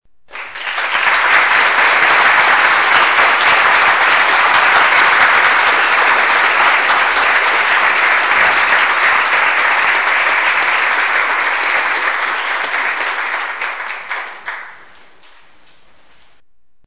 APLAUSOS Y MAS APLAUSOS
Tonos EFECTO DE SONIDO DE AMBIENTE de APLAUSOS Y MAS APLAUSOS
Aplausos_y_mas_aplausos.mp3